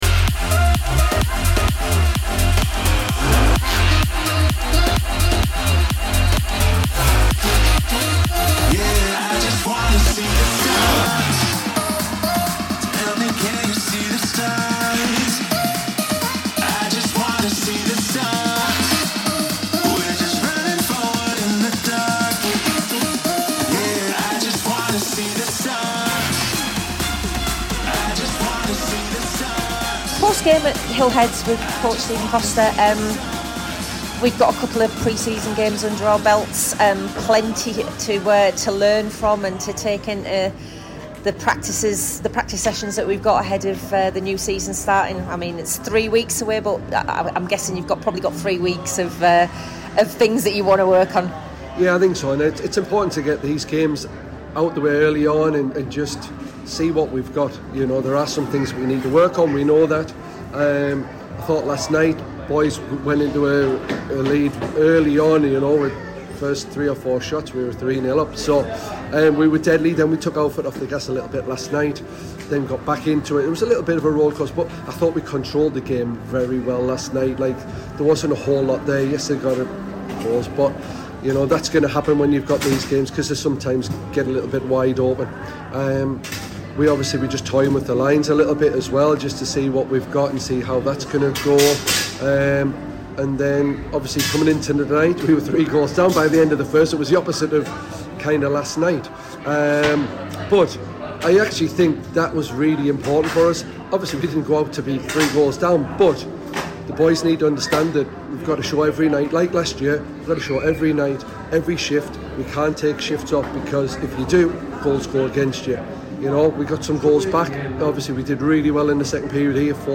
Preseason postgame with coach